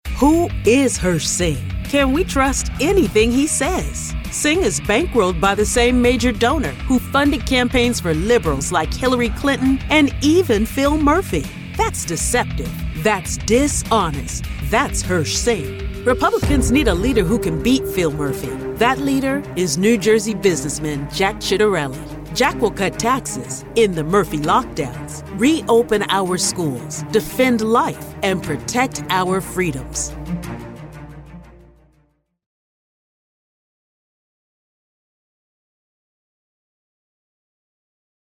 Female Republican Voices
Variety of great voice actors with pro home studios and Source Connect.